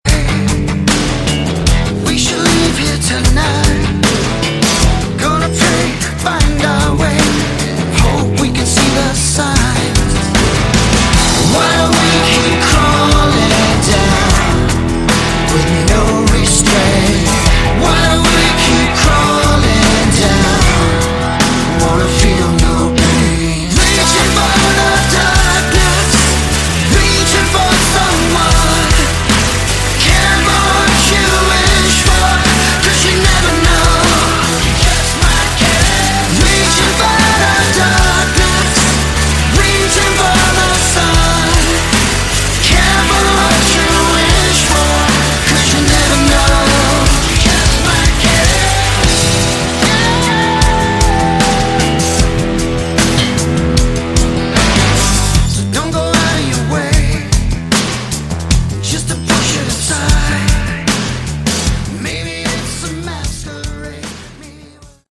Category: Melodic Rock
lead vocals, guitars, piano
drums, percussion, electronics
keyboards
electric and acoustic bass, vocals